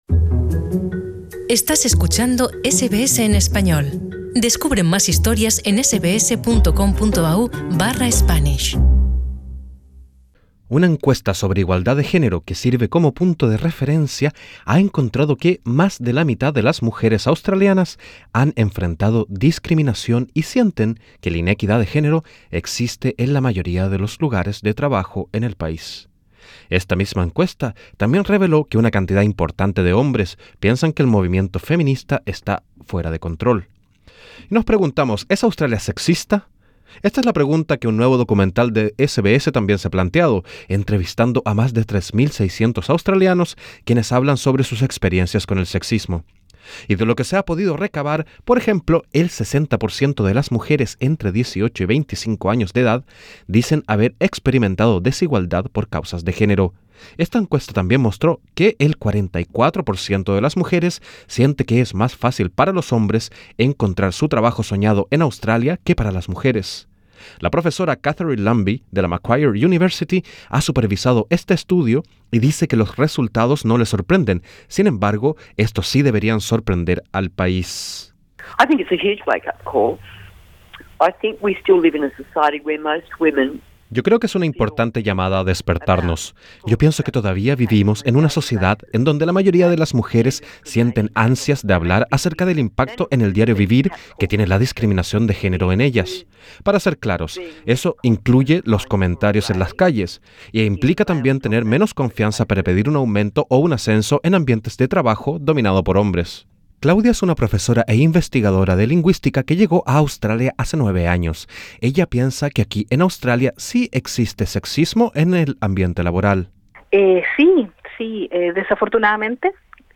En SBS conversamos con algunas mujeres que se han desempeñado en diferentes trabajos en el país. Ellas nos cuentan sus experiencias y su visión del problema.